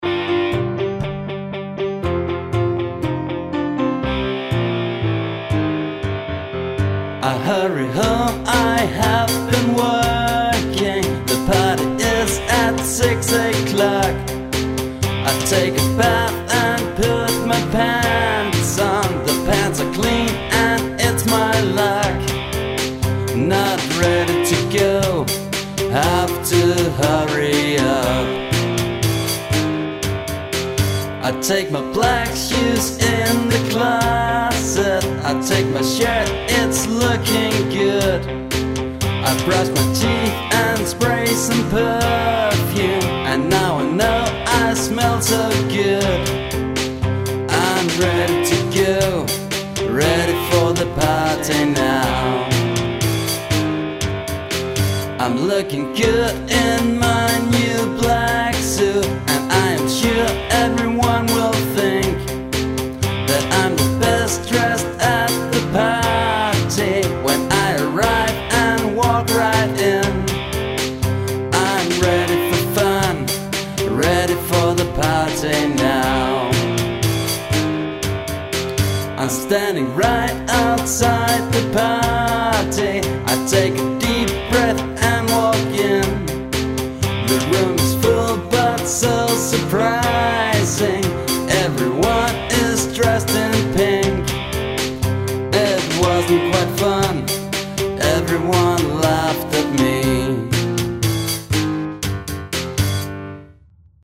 Songs